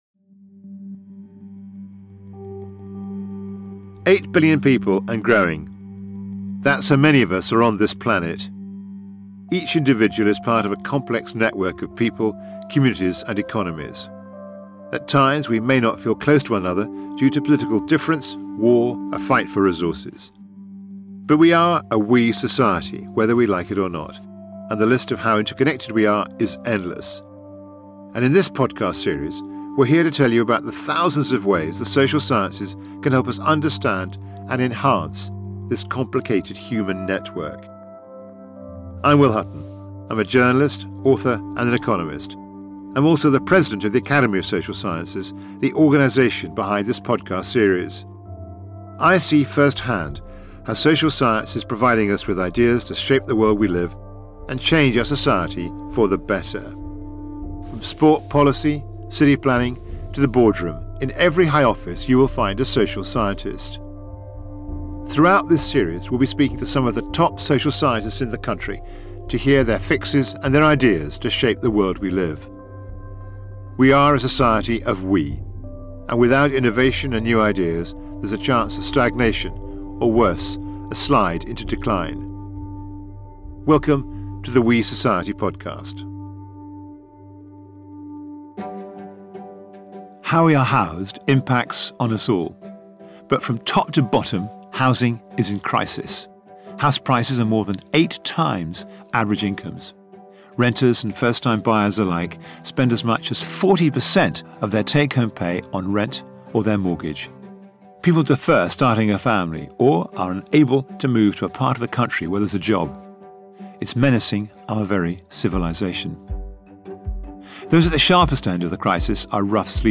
She joins Will Hutton in a conversation that touches on the critical need for more social housing, the spiralling number of rough sleepers, the need for a radical rethink in landownership practices, and how we perceive housing in the UK.